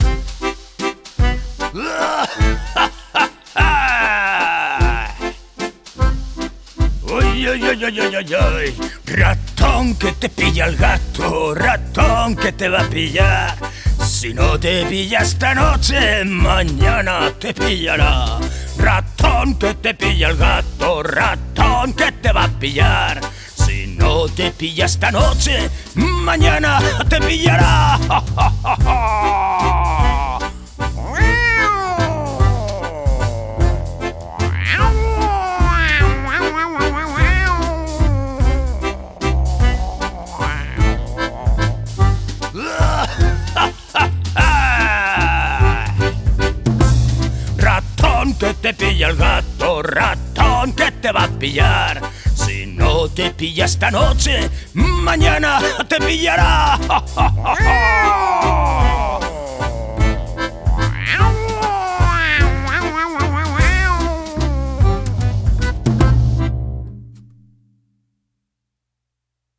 comptines